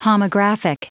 Prononciation du mot homographic en anglais (fichier audio)
Prononciation du mot : homographic